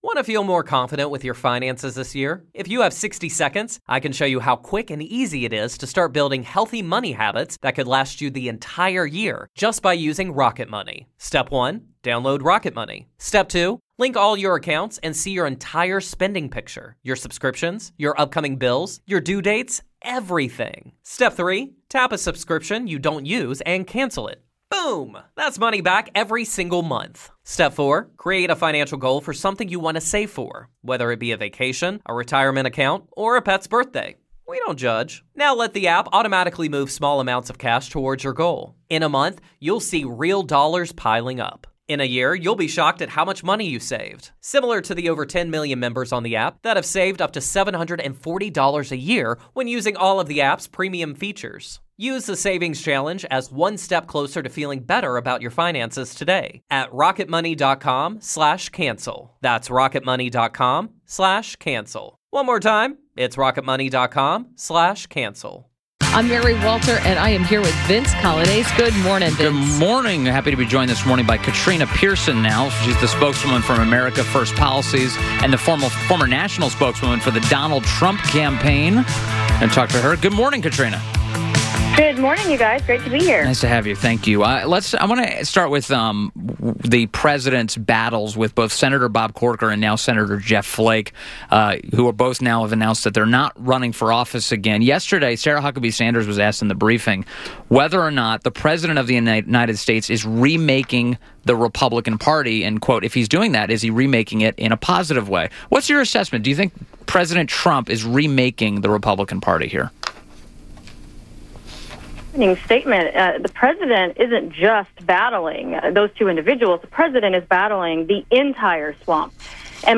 WMAL Interview - KATRINA PIERSON - 10.25.17